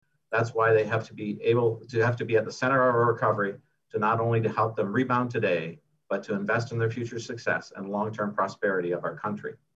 Screengrab of ZOOM announcement by Bay of Quinte riding MP Neil Ellis (far left, middle row) Monday June 14 2021.
Bay of Quinte riding MP Neil Ellis made the announcement Monday and said the COVID-19 pandemic hit young people especially hard.